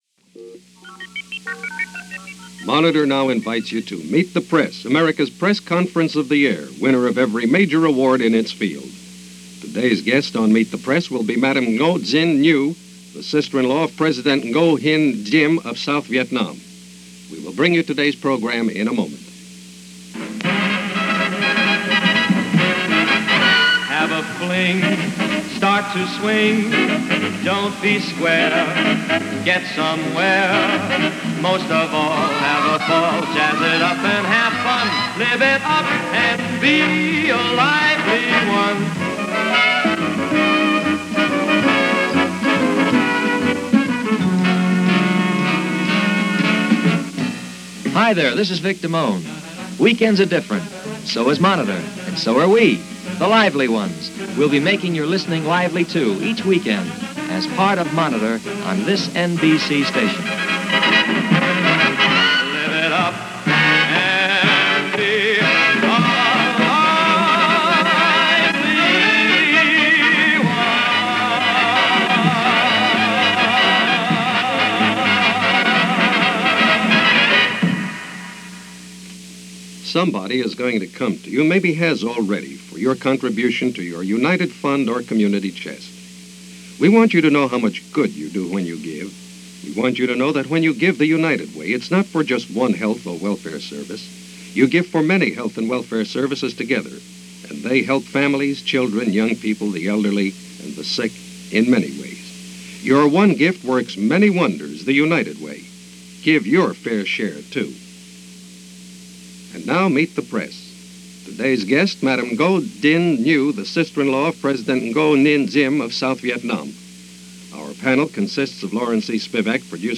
Madame Nhu Visits America - 1963 - Her Meet The Press appearance of October 13, 1963 - Past Daily Reference Room.
Meet-The-Press-Madame-Nhu-1963.mp3